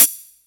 Closed Hats
Hat (78).wav